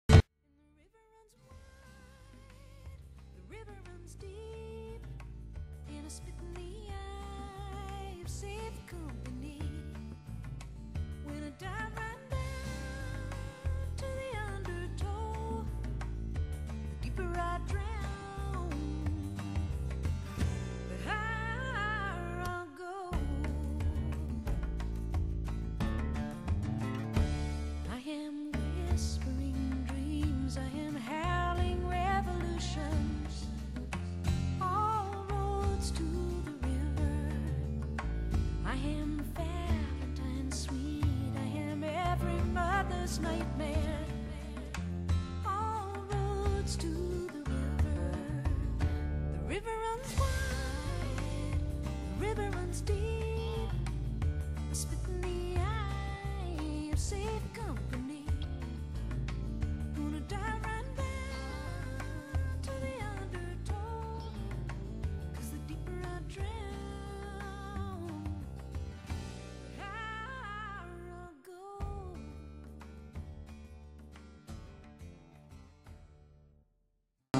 【所屬類別】 CD唱片　　爵士及藍調